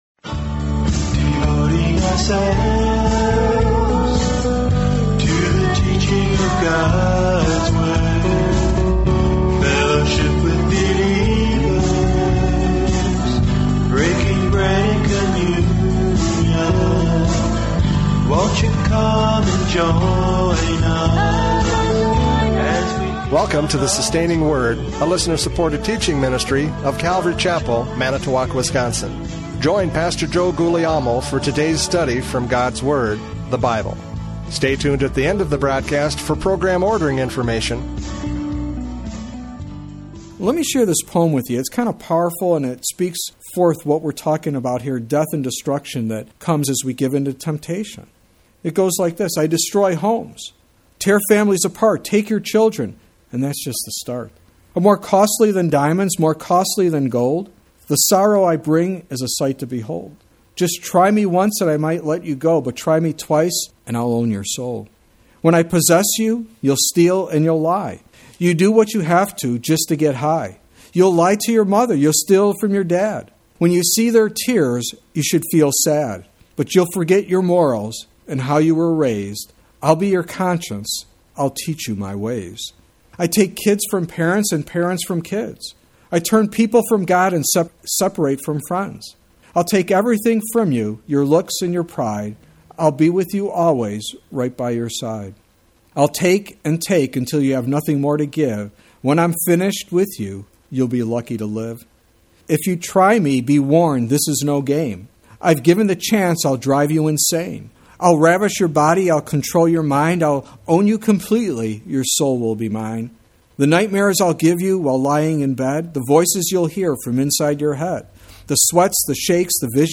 Judges 16:1-3 Service Type: Radio Programs « Judges 16:1-3 Samson Tempts Himself!